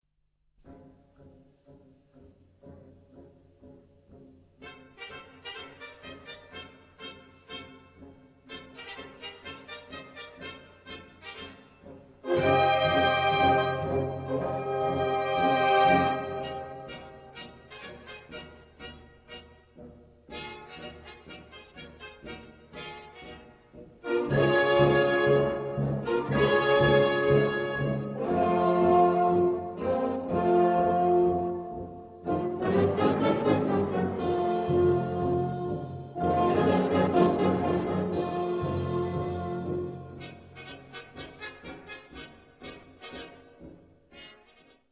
Dotted rhythms highlight the march.
The march ends in the faster "Vivo" tempo.